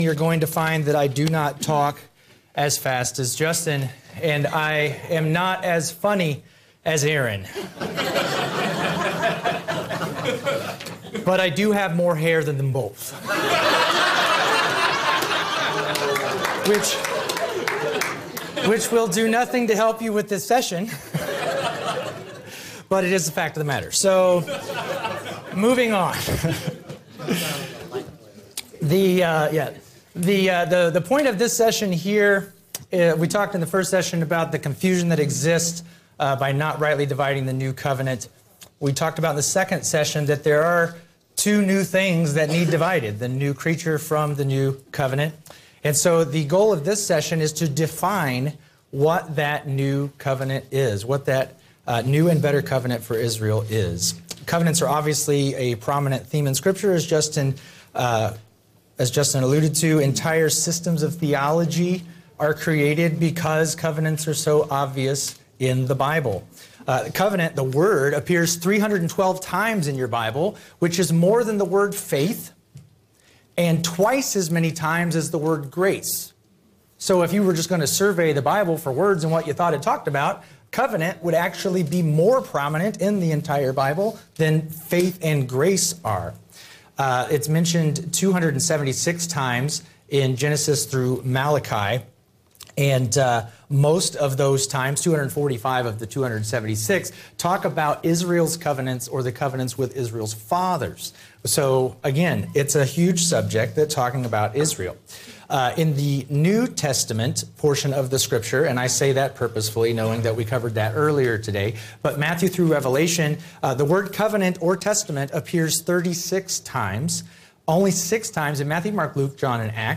The 2024 Seminar, “New Covenant Confusion,” was taught over the weekend of October 19th and 20th. While most Christians understand that the church today is not under Israel’s Old Covenant, there is much confusion about the church’s relationship to Israel’s New Covenant.